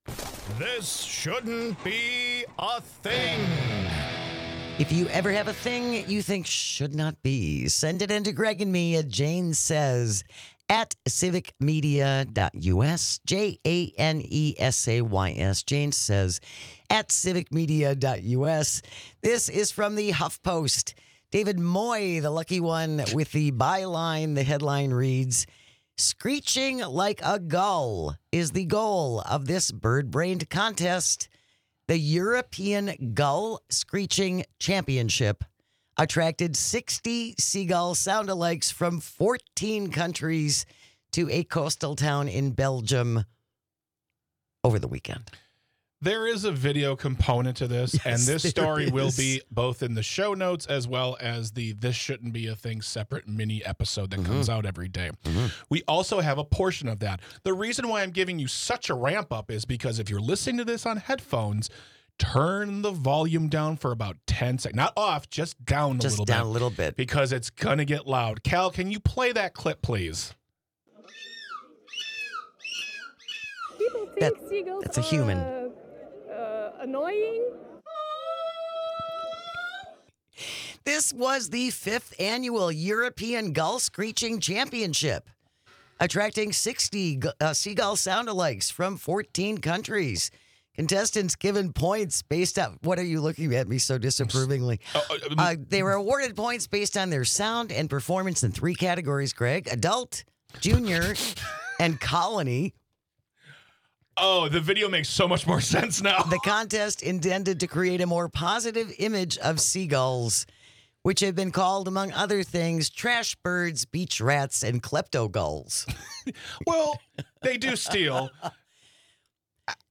If you live in Europe, have the costume and the talent, you might just win a contest that nobody asked to be a thing, ever. If you're listening with headphones, we suggest you turn it down a little bit for this one.